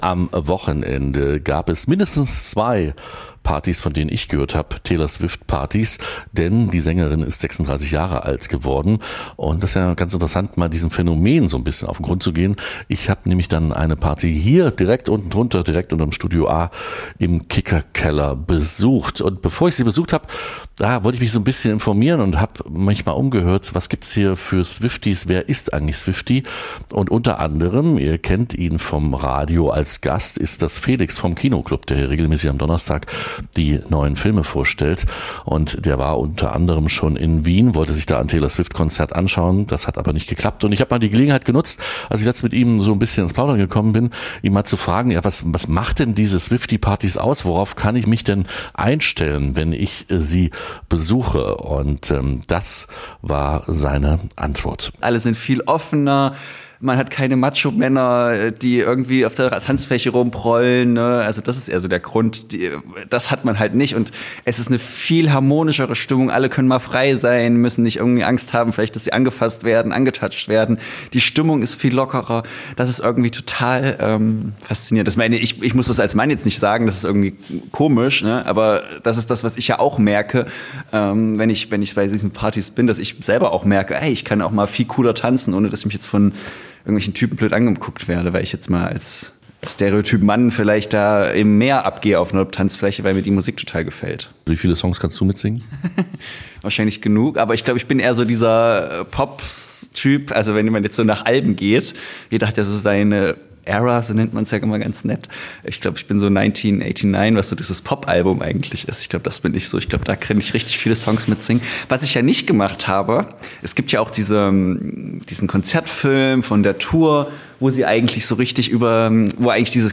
Ihre Anhängerinnen und Anhänger bezeichnen sich selbst als Swifties. Mitte Dezember gab es anlässlich des 36. Geburtstags der Sängerin zwei Swiftie-Partys in Erfurt.
REPORTAGE Taylor Swift.mp3